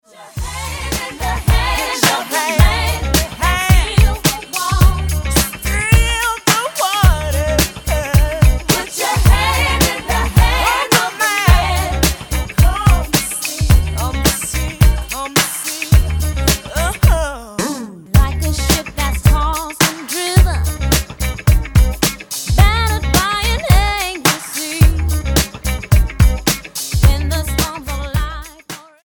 R&B gospel trio